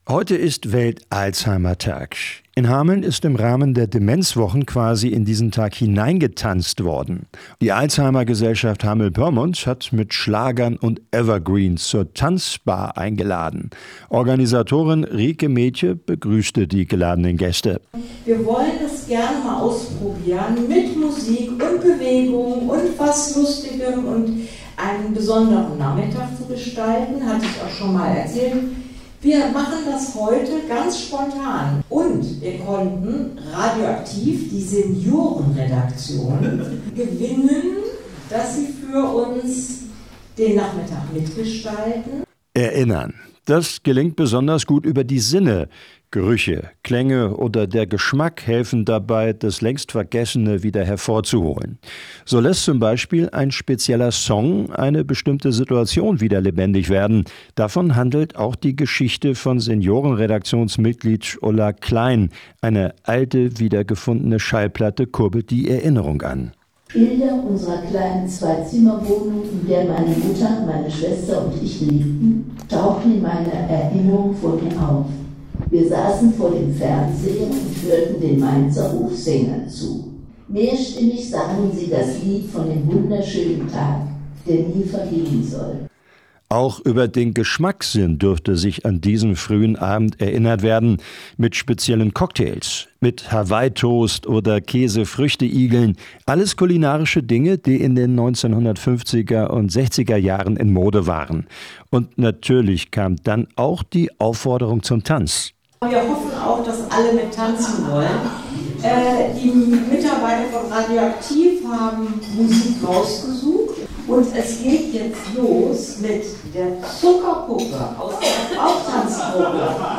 Hameln: „Tanzbar“ der Alzheimergesellschaft Hameln-Pyrmont lud mit Schlagern und Evergreens ein zum Erinnern und Tanzen